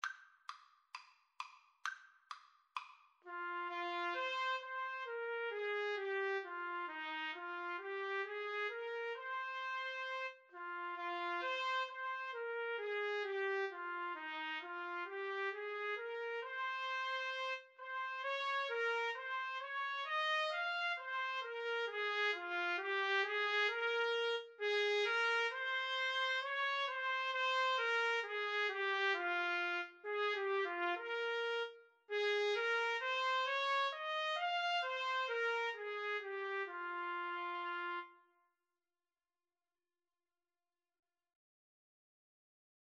The melody is in the minor mode.
Slow two in a bar feel = c. 66
Trumpet Duet  (View more Easy Trumpet Duet Music)